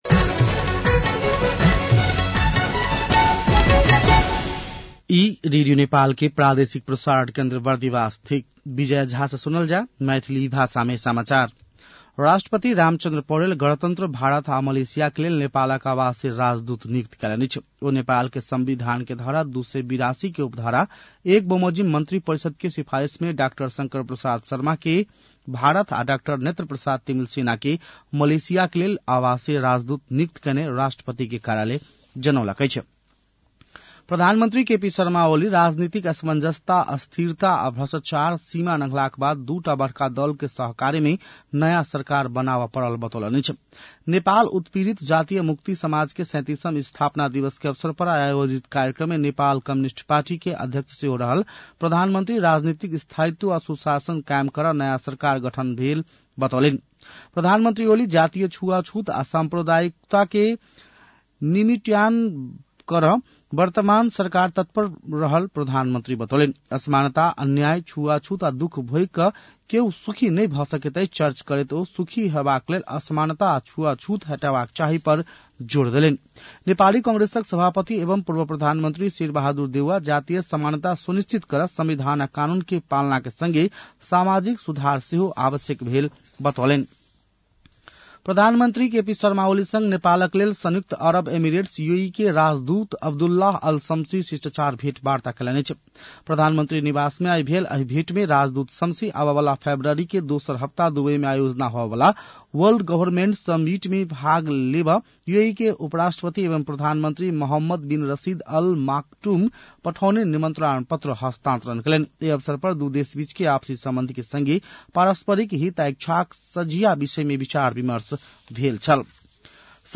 मैथिली भाषामा समाचार : ४ पुष , २०८१